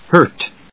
/hˈɚːt(米国英語), hˈəːt(英国英語)/